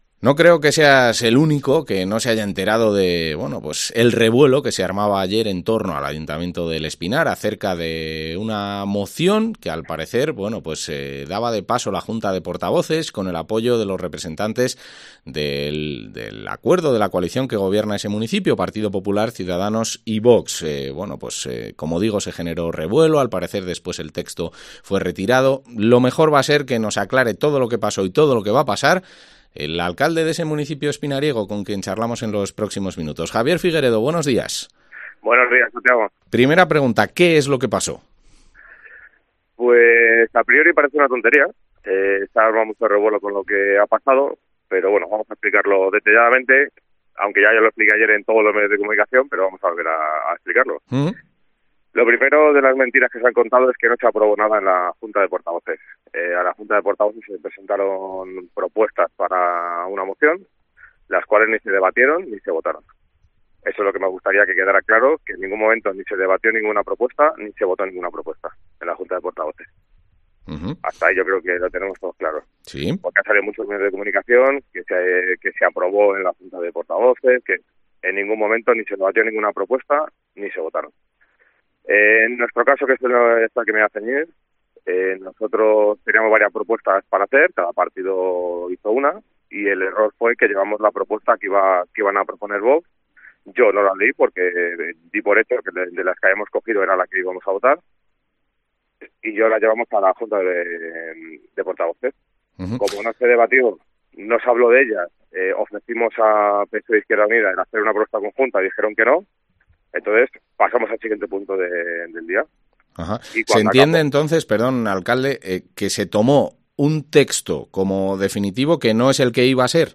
Entrevista al alcalde de El Espinar, Javier Figueredo